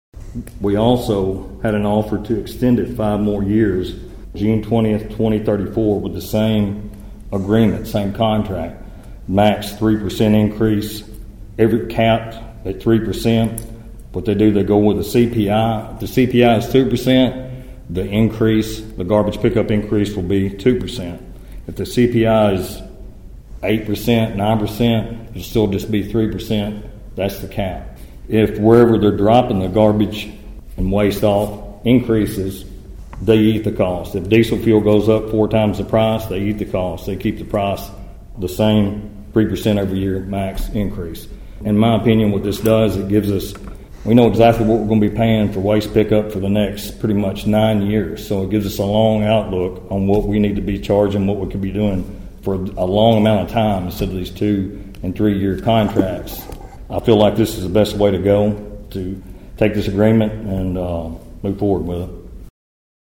McTurner also informed board members of an opportunity to expand the city waste contract with Unified Disposal Partner Holding LLC.(AUDIO)